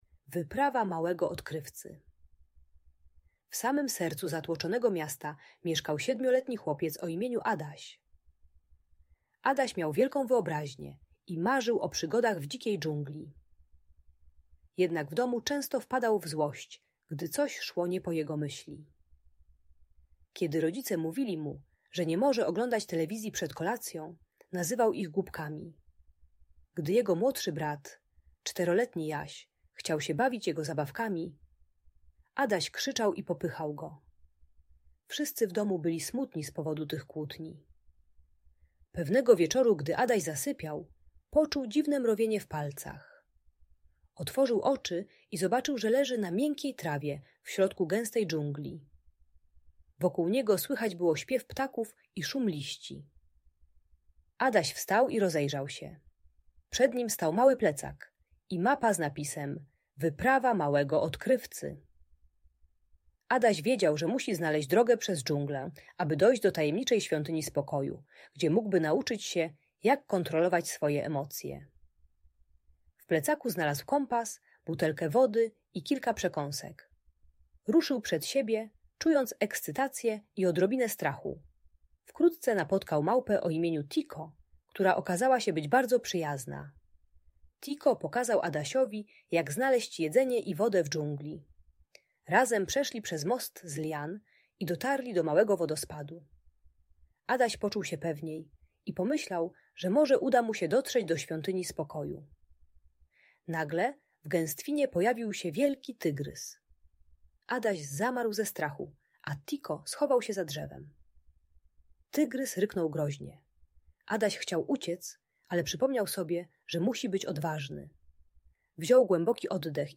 Wyprawa Małego Odkrywcy - Audiobajka